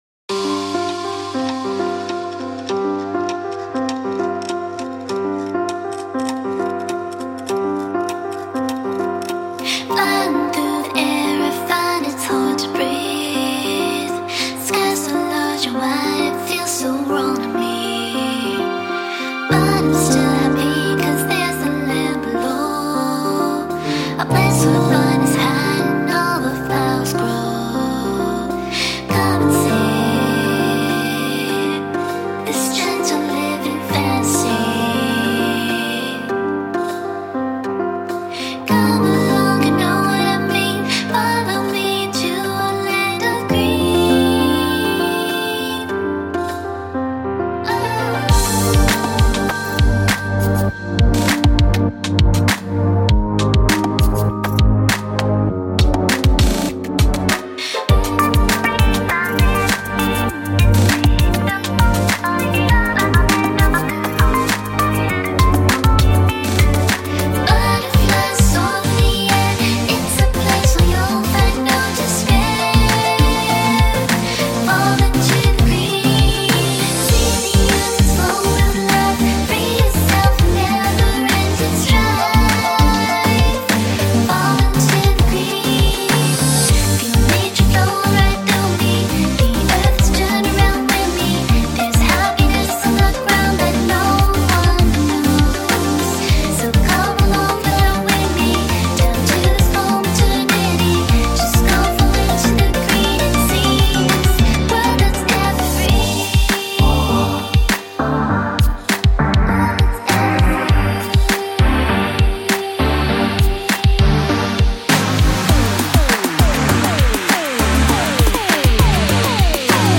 (There are also a few body percussions in there)
Key: G Minor BPM: 100